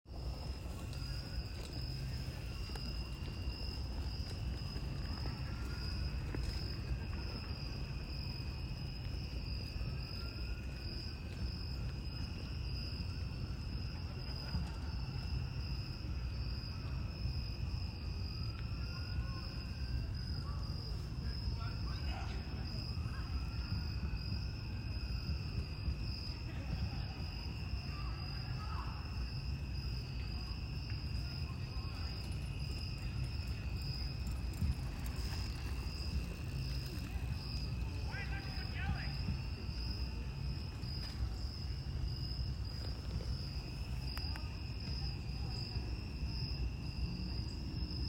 It's hard to appreciate the calming sound of peep frogs at night without
hearing their chirps yourself.
frogs.m4a